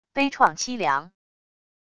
悲怆凄凉wav音频